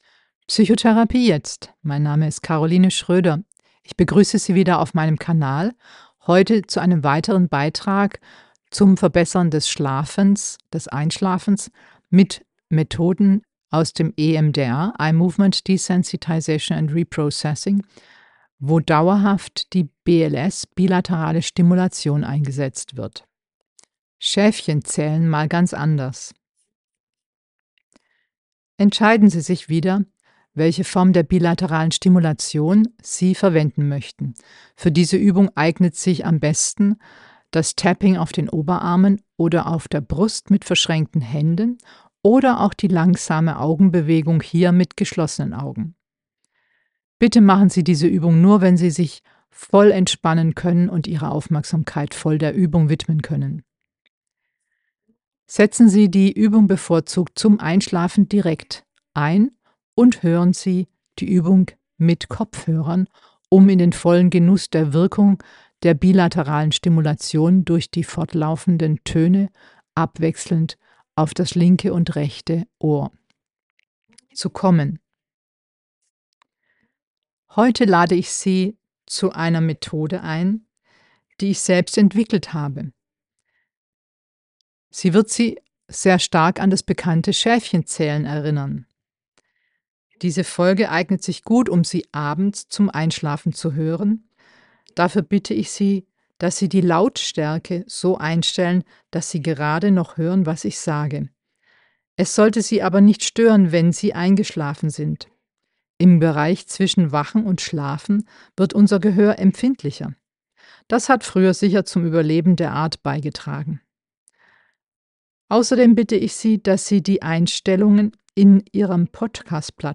Stimulation über die Töne abwechselnd im linken und rechten